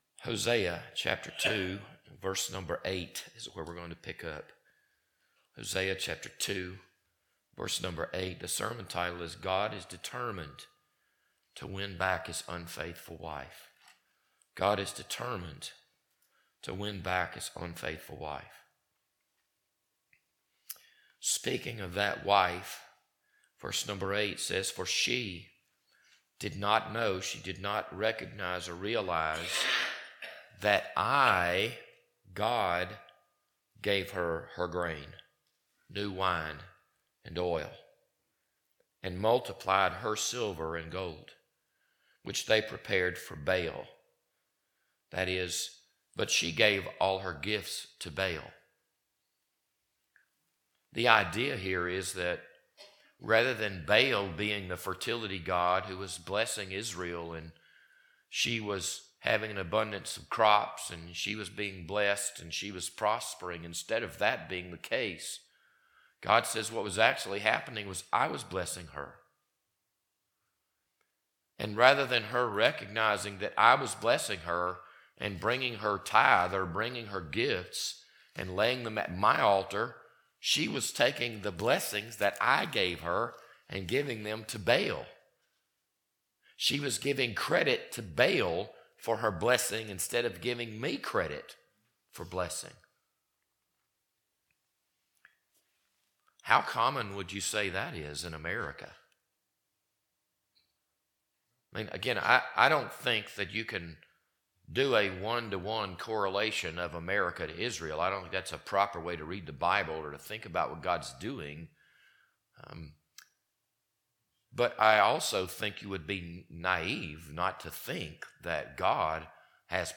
This Sunday evening sermon was recorded on August 3rd, 2025.